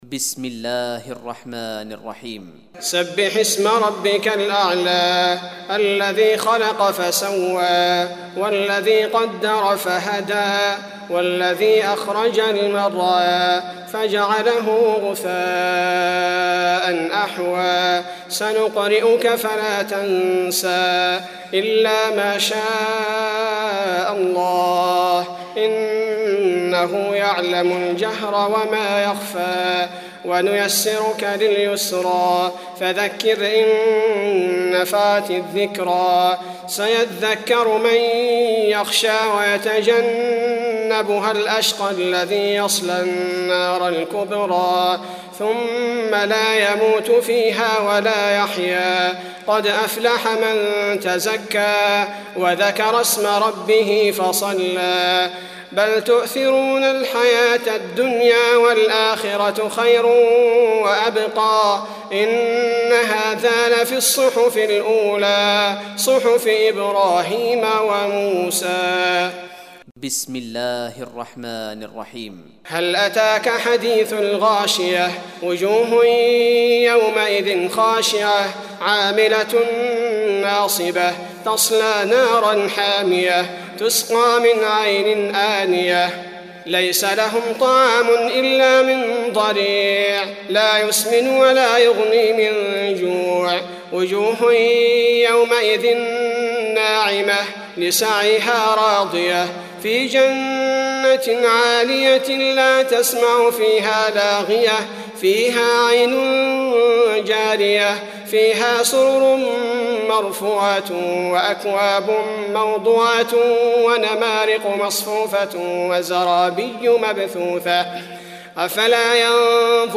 تراويح ليلة 29 رمضان 1419هـ من سورة الأعلى الى الناس Taraweeh 29th night Ramadan 1419H from Surah Al-A'laa to An-Naas > تراويح الحرم النبوي عام 1419 🕌 > التراويح - تلاوات الحرمين